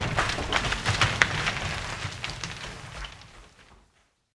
vein_wreck.ogg